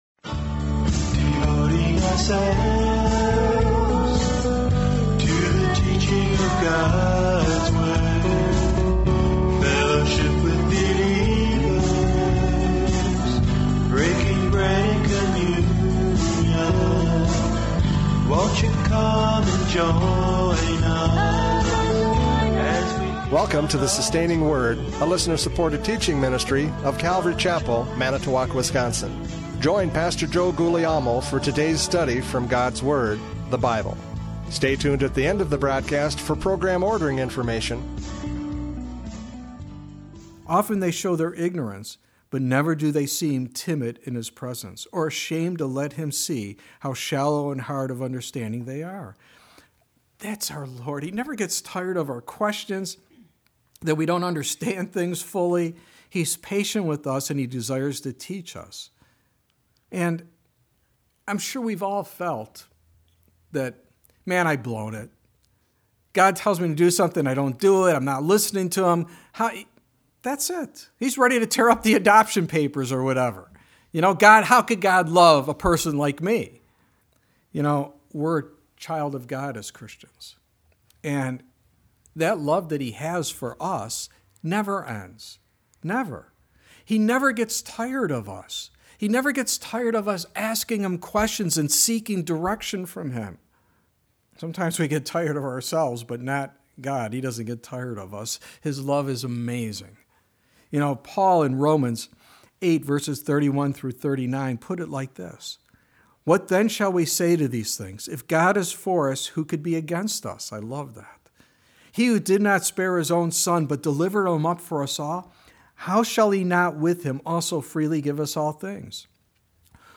John 14:4-14 Service Type: Radio Programs « John 14:4-14 Do You Know the Way?